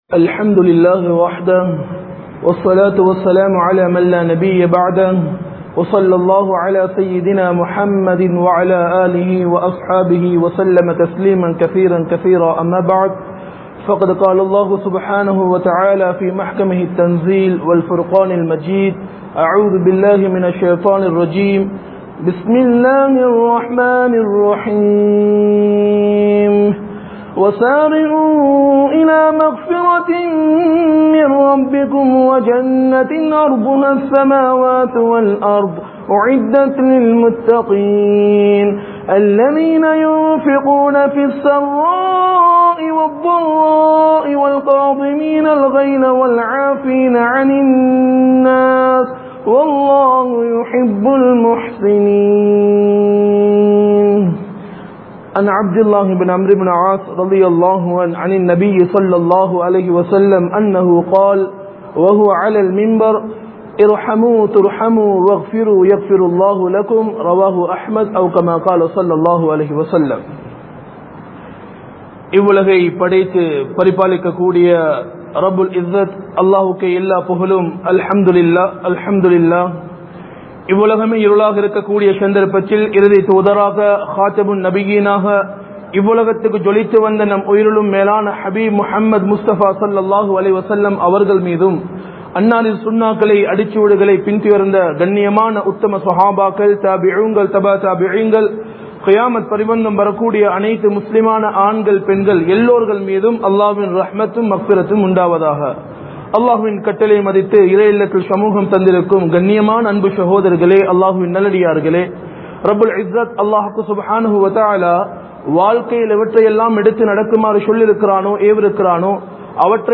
Allah Ungalai Mannikka Veanduma? (அல்லாஹ் உங்களை மண்ணிக்க வேண்டுமா?) | Audio Bayans | All Ceylon Muslim Youth Community | Addalaichenai
Majma Ul Khairah Jumua Masjith (Nimal Road)